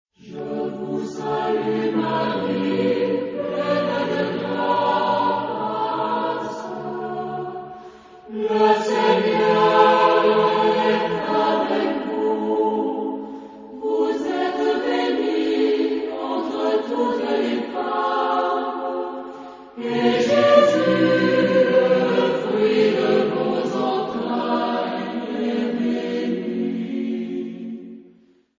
Epoque: 20th century
Genre-Style-Form: Sacred ; Choir
Mood of the piece: prayerful
Type of Choir: SATB  (4 mixed voices )
Tonality: B minor